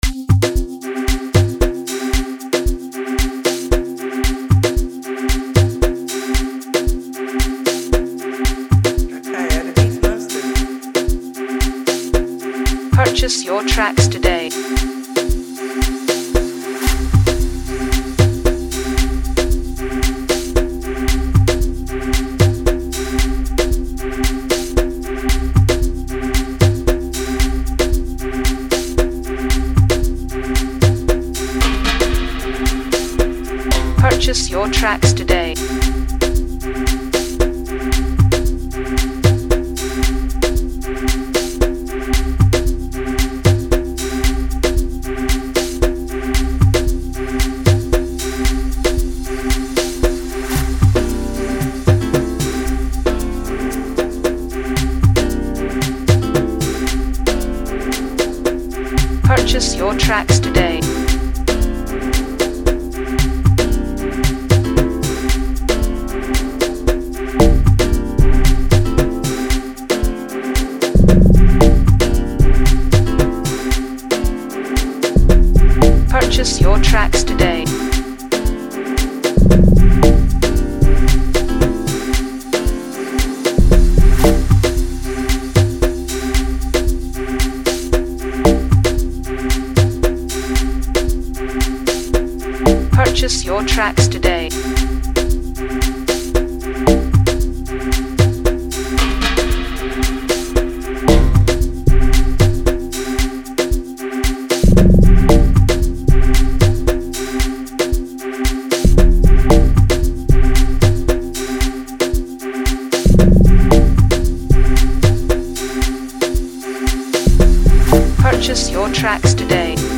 fresh Amapiano vibes
an electrifying instrumental